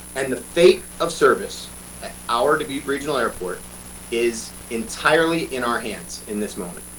On Tuesday, the Dubuque Area Chamber of Commerce and Greater Dubuque Development Corporation hosted a virtual town hall to provide updates about Dubuque’s air service.